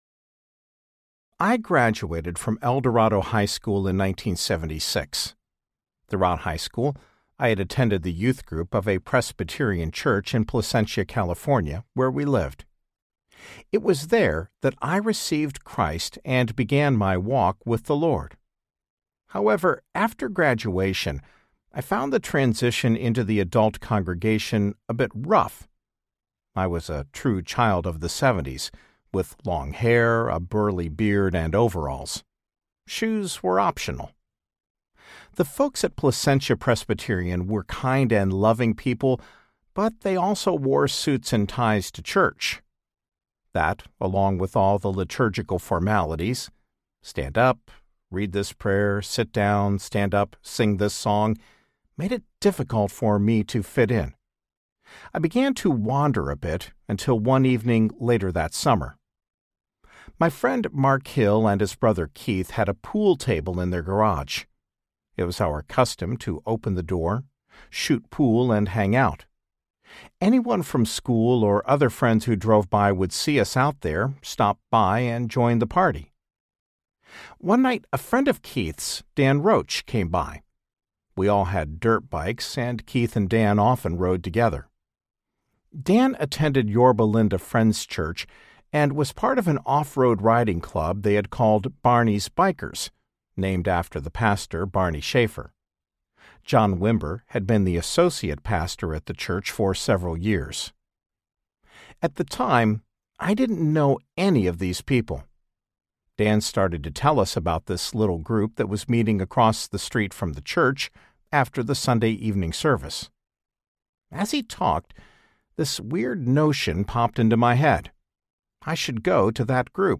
Never Trust a Leader Without a Limp Audiobook